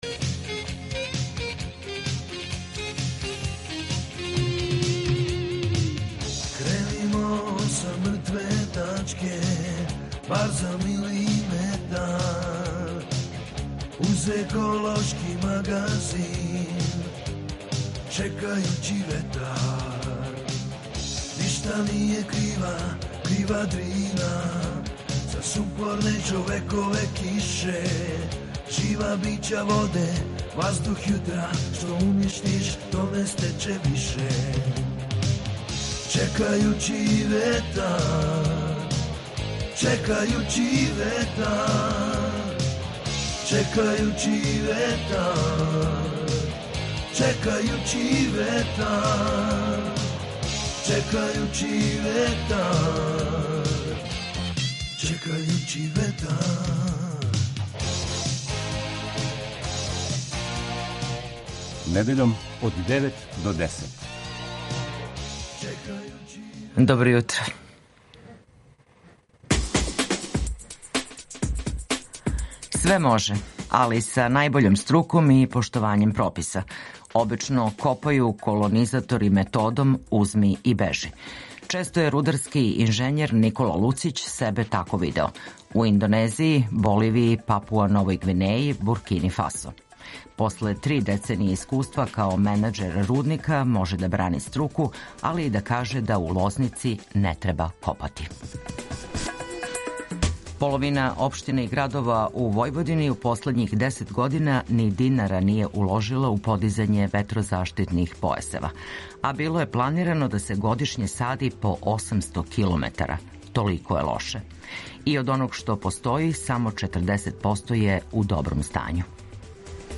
PRESLUŠAJ: Čekajući vetar - ekološki magazin Radio Beograda 2 koji se bavi odnosom čoveka i životne sredine, čoveka i prirode.